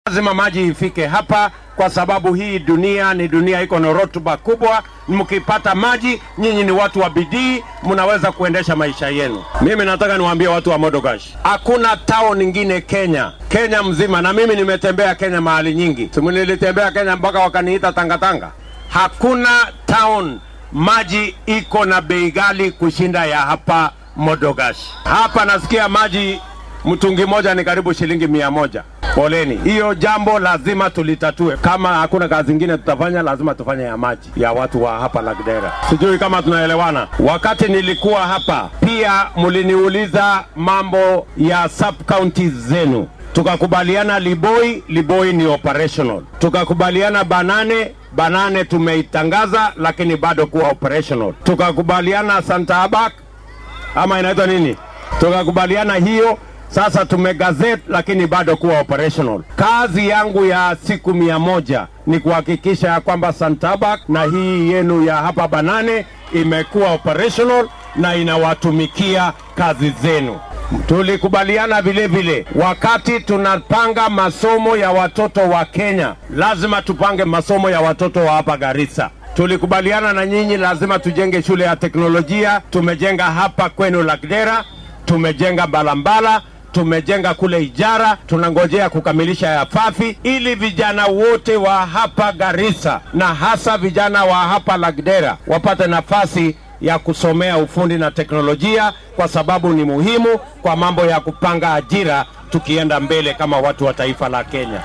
Madaxweyne ku xigeenka dalka ahna musharrax madaxweyne William Ruto oo shalay galab ololihiisa siyaasadeed geeyay magaalada Madogaashe ee deegaan baarlamaneedka Lagdera, ismaamulka Garissa ayaa ballanqaaday in haddii hoggaanka dalka uu ku guulaysto 9-ka bisha Siddeedaad ee sanadkan , uu 100-ka cishe ee ugu horreeya maamulkiisa wax ka qaban doono dhibaatooyin dhanka biyaha iyo deegaamaynta ah oo ka jira Lagdera.